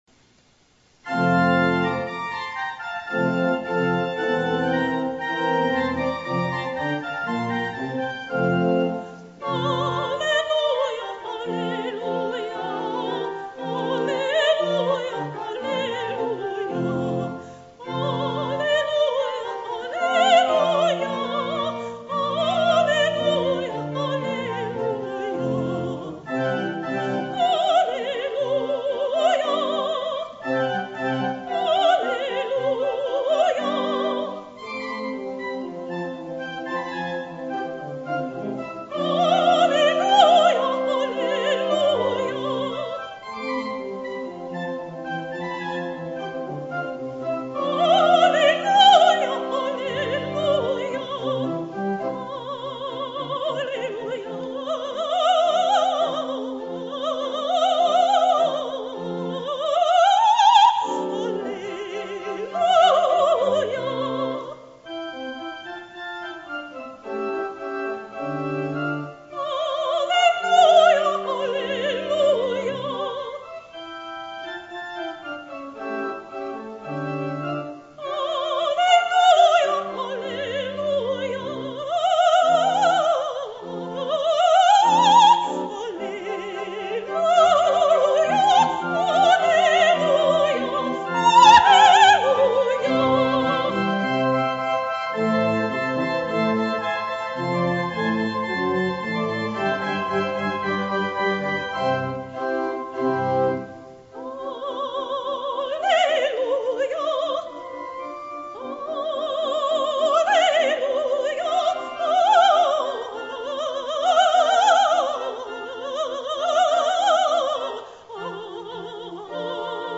soprano sings W A Mozart's "Alleluia"